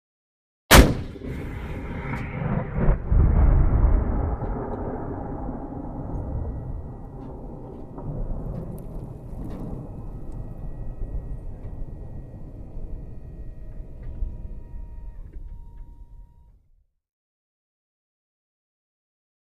M-1 Tank Cannon: Fire with Echo; Large Tank Cannon Fire With Long Echo And Flame Whoosh Recoil. Medium Perspective.